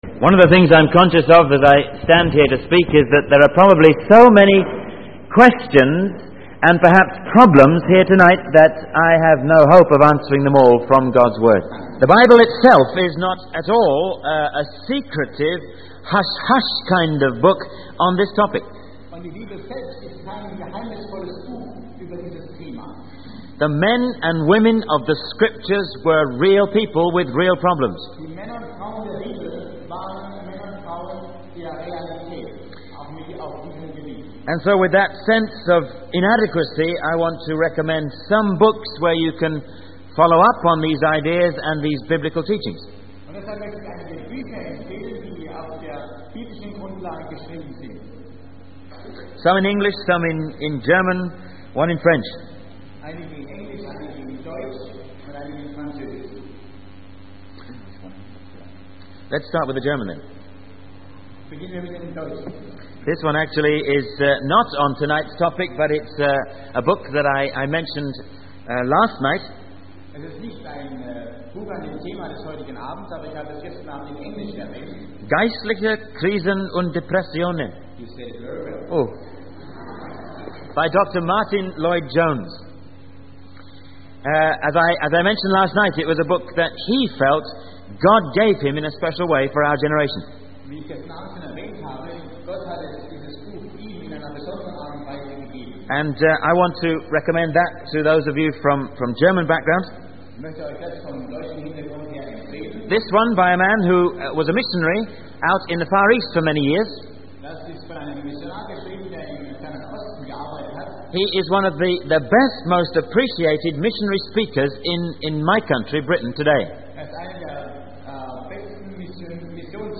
In this sermon, the speaker begins by emphasizing the importance of honoring God with our bodies, as we have been bought at a price. He then directs the audience to 1 Thessalonians chapter 4, where he encourages them to take the word of God seriously and seek understanding from it.